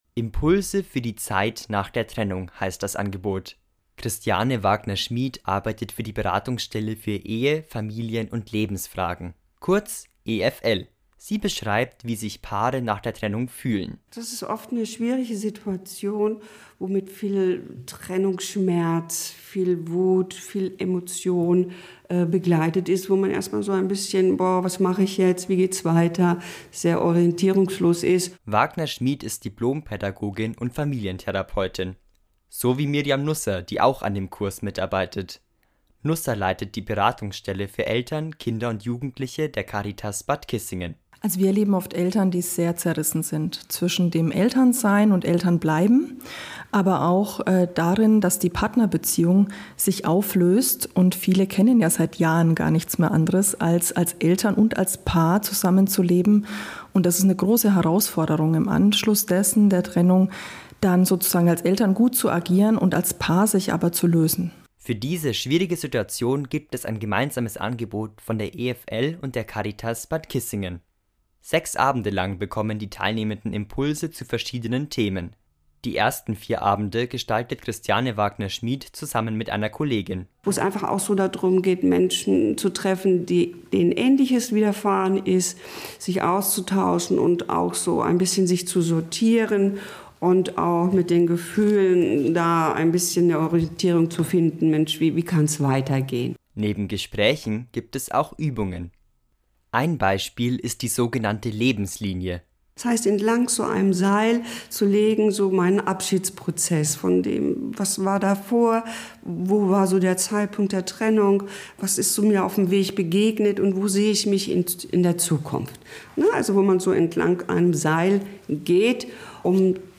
hat mit zwei
Expertinnen darüber gesprochen.